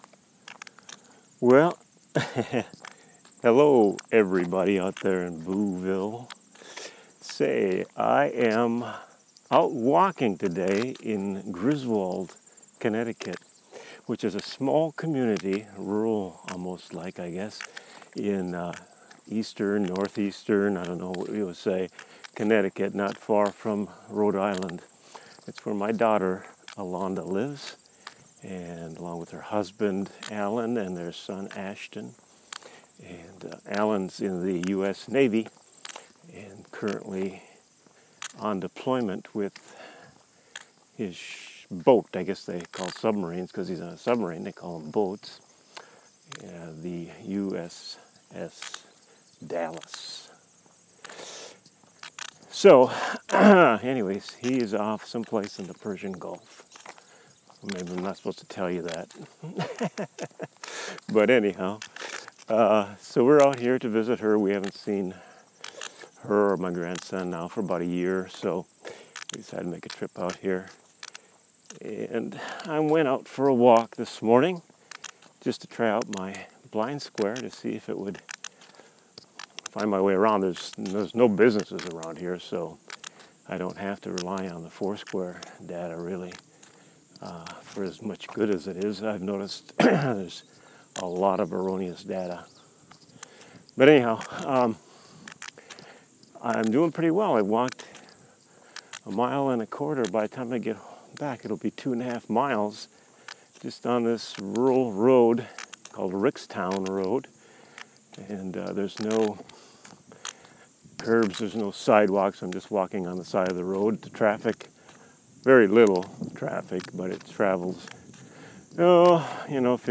A walk in rural Connecticut using the BlindSquare app.
The area is semi rural and the roadway has no sidewalk or curb.